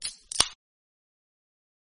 beer_can_opening.mp3